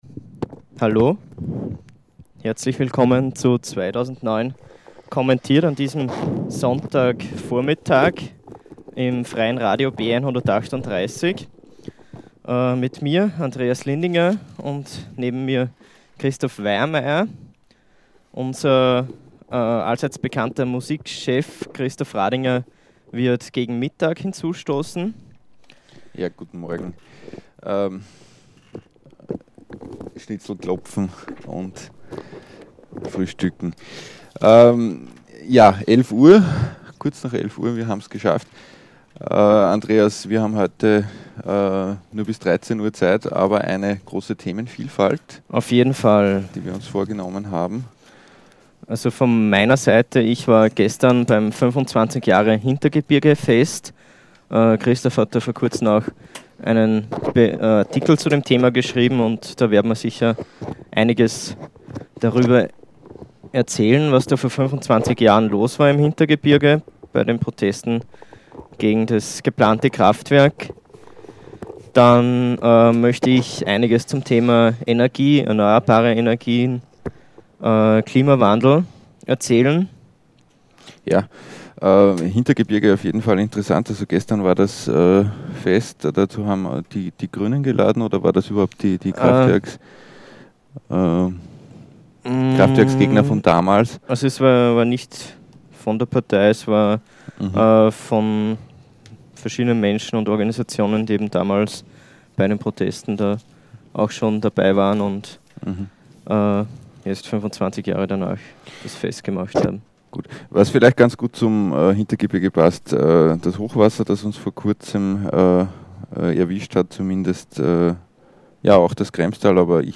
Live im Freien Radio B138.